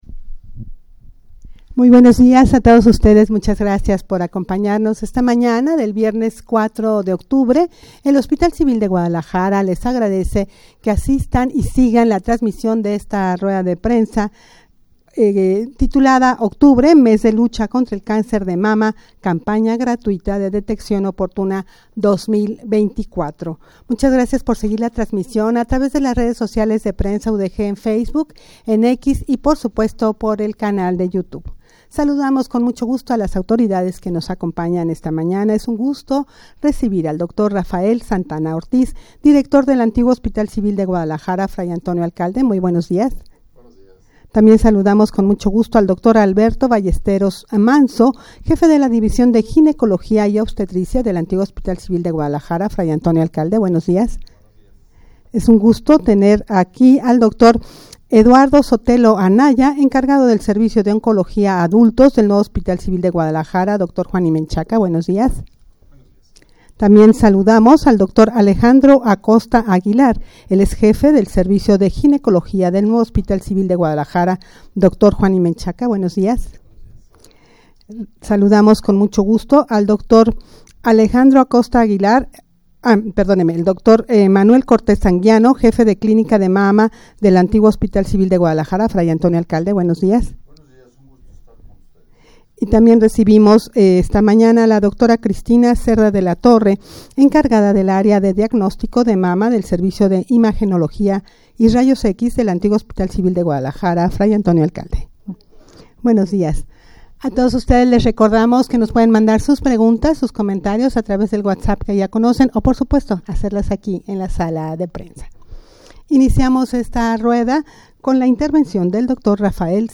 Audio de la Rueda de Prensa